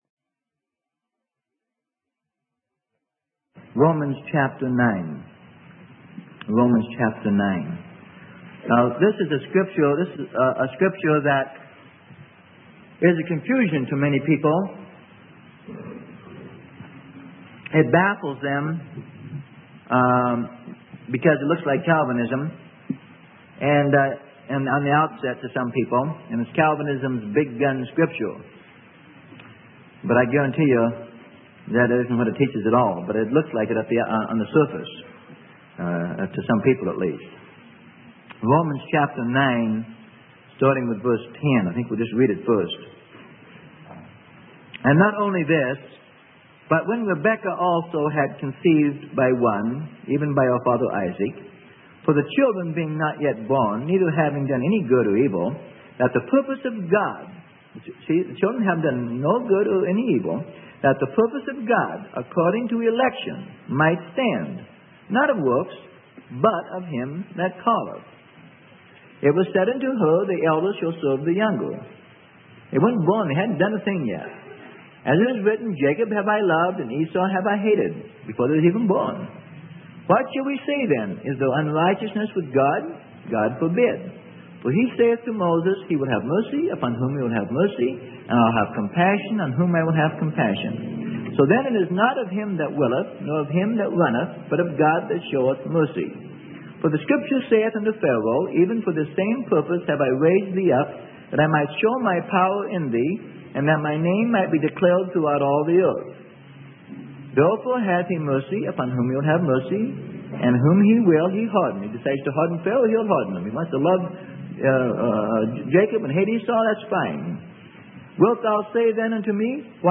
Sermon: Calvinism - Part 12 - Freely Given Online Library